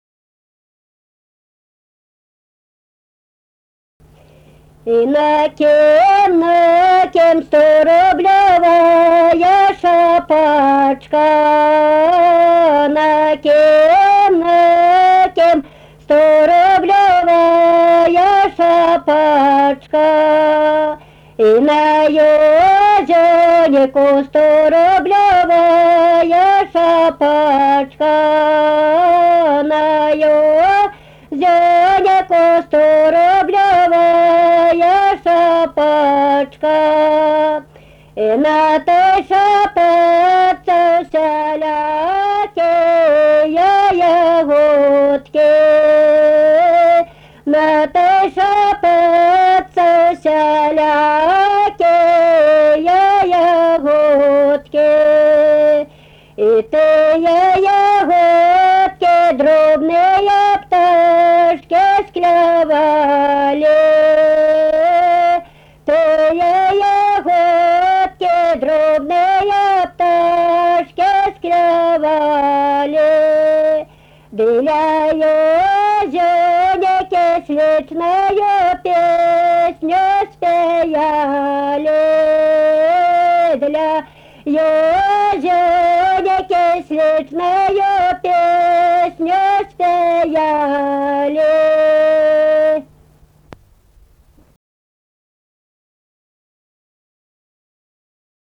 Kavaltiškė, Kavoliškės k.
Atlikimo pubūdis vokalinis
Baltarusiška daina